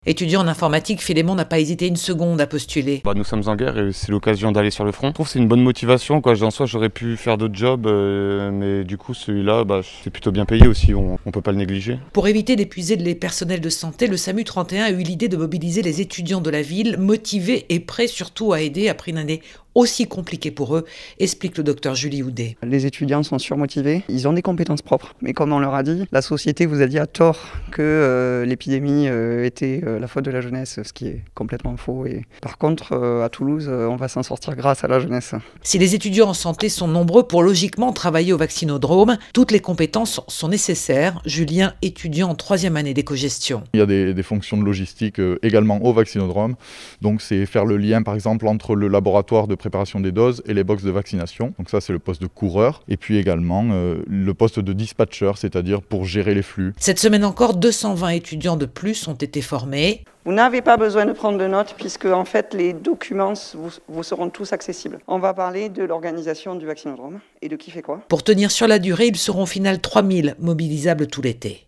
Reportage